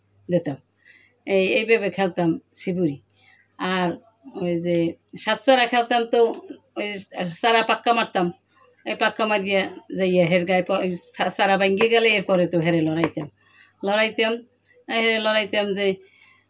valid_barishal (104).wav